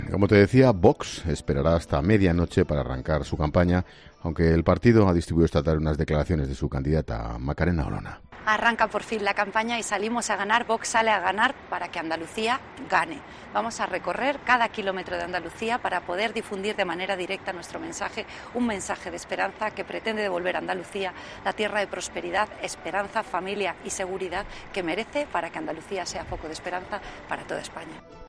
Teresa Rodríguez, candidata de Adelante Andalucía comenzó su andadura en Jerez de la Frontera con un acto a las 20.30 horas.